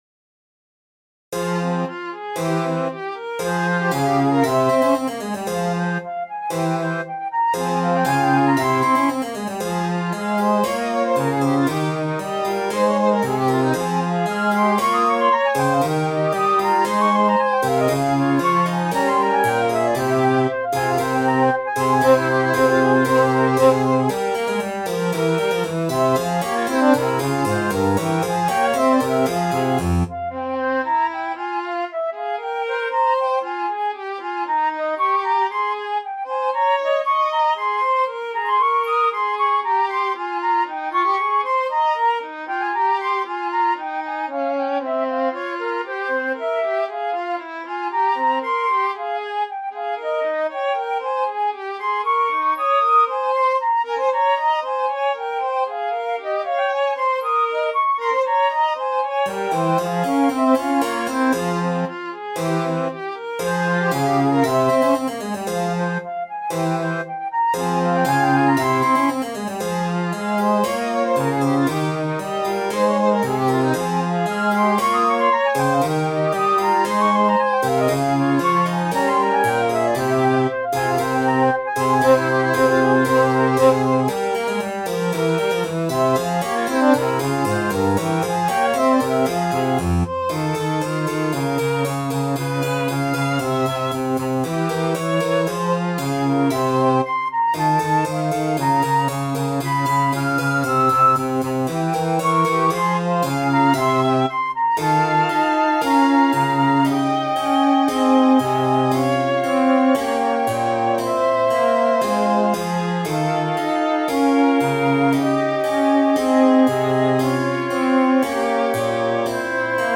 Here a duet for flute and Violin with continuo in a baroque imitating style with some doble conterpoint I composed recently. In the ritornello the flute basically repeats violin phrases in the higher octave. Three episodes are placed between the ritornellos with some flute and Violin solos.